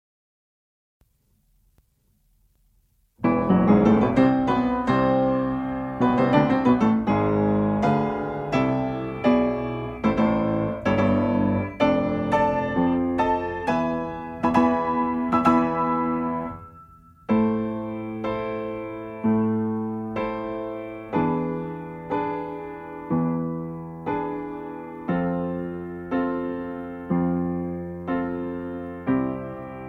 Nagranie bez metronomu, uwzględnia rubata.
I wersja (szybsze tempa):
Andante sostenuto: 68 bmp
Nagranie dokonane na pianinie Yamaha P2, strój 440Hz